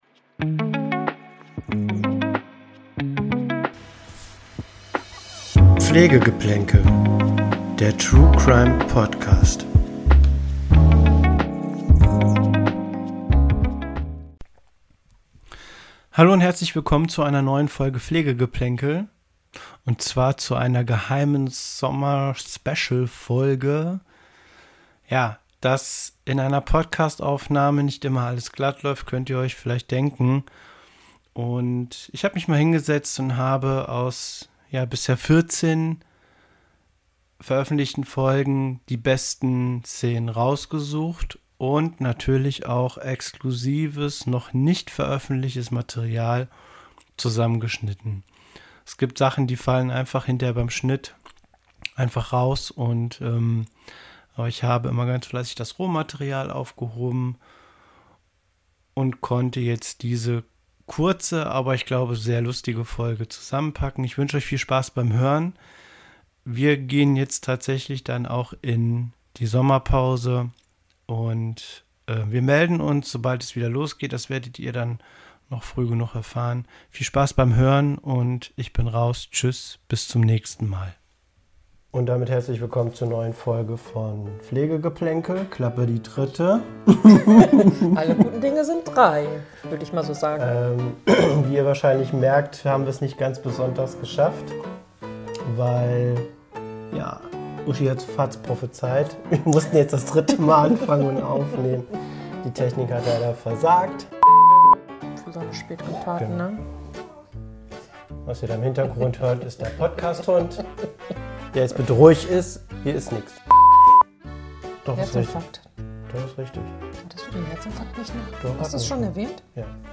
Patzer, Versprecher und unerwartete Situationen gehören einfach dazu. Wir haben die besten Momente und bisher unveröffentlichtes Material aus 14 Folgen Pflege Geplänkel für euch zusammengestellt.